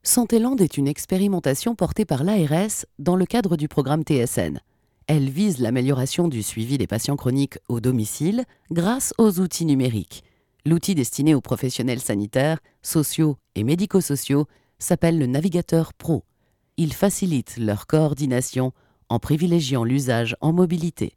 Deep voice, warm, sexy if required, used to sing rock jazz and blues, smoother on demand, and many others variations available.
Sprechprobe: Industrie (Muttersprache):